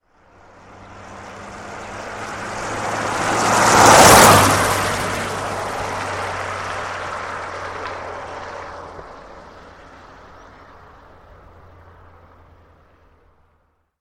Звуки машины, снега
Звук автомобиля на заснеженной дороге